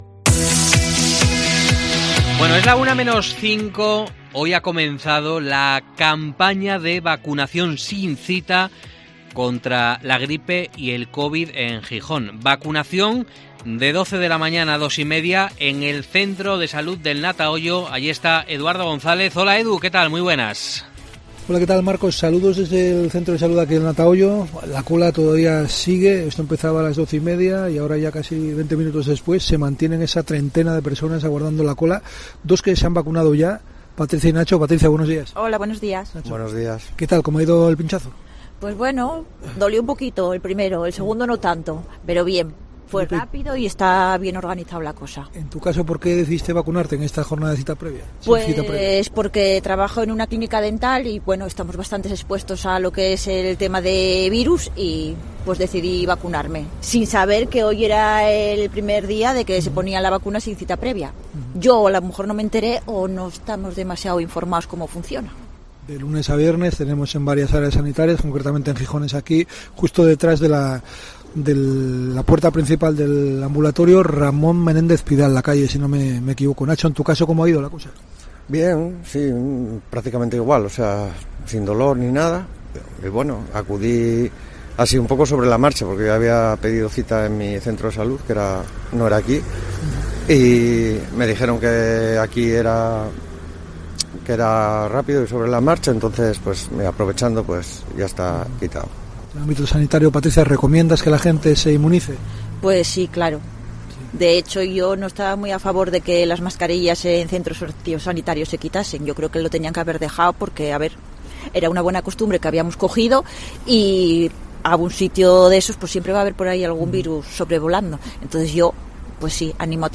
Los ciudadanos explican en COPE Gijón porque se vacunan ahora contra la gripe y el Covid-19